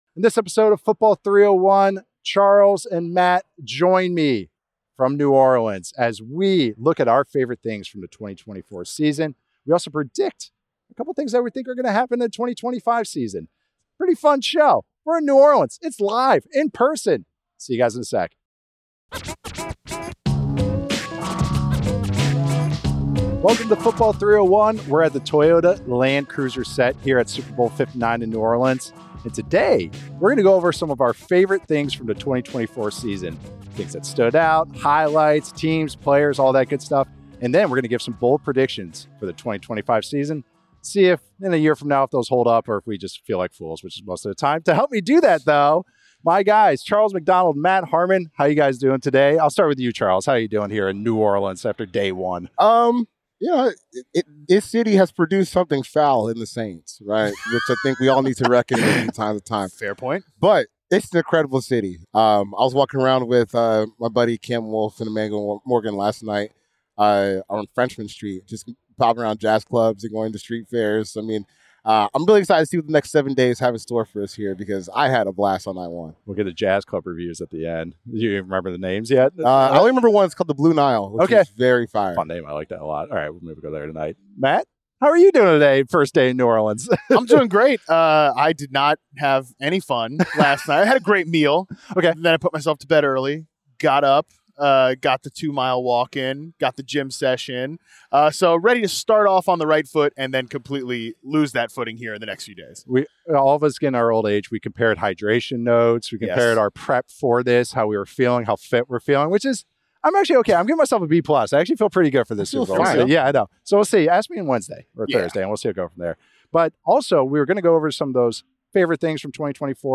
are live and in person at Radio Row in New Orleans as they react to some breaking news, give their favorite moments from the 2024 season and some spicy bold predictions to watch for in 2025.